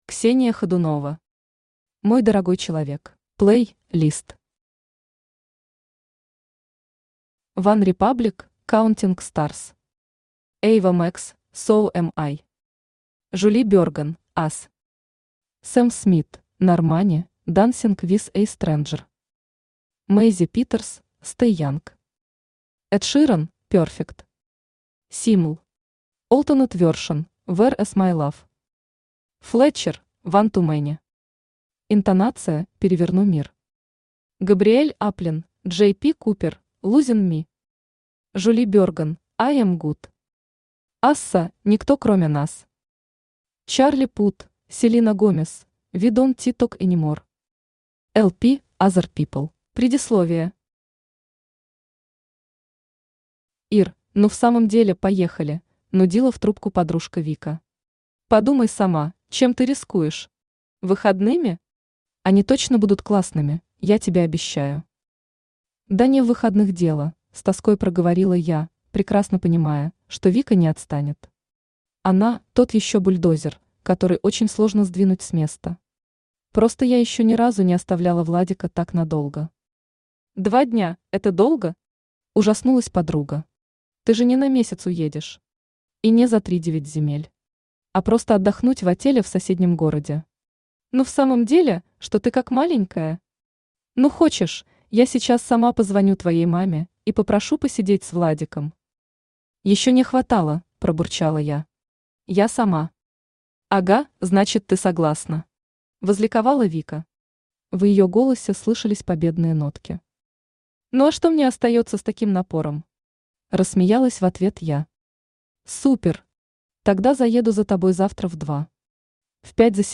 Аудиокнига Мой дорогой человек | Библиотека аудиокниг
Aудиокнига Мой дорогой человек Автор Ксения Александровна Хадунова Читает аудиокнигу Авточтец ЛитРес.